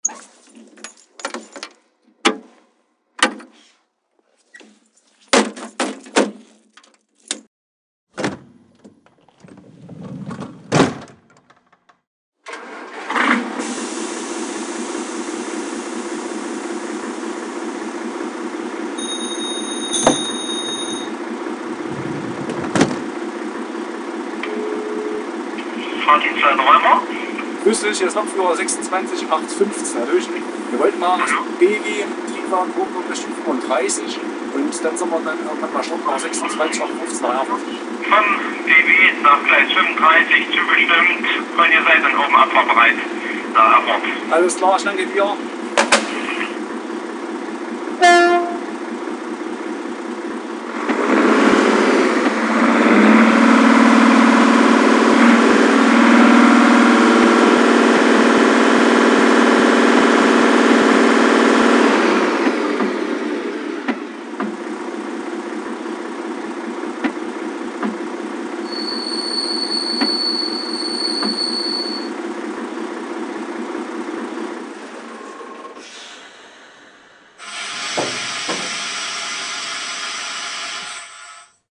Dieseltriebwagen BR 772 DB AG mit Sound
• werksseitig eingebauter PluX22 Sounddecoder mit passendem Sound
Digitalfunktionen: Fahrsound mit umfangreichen Soundfunktionen, digital schaltbare Innenbeleuchtung, digital schaltbare Zugzielanzeige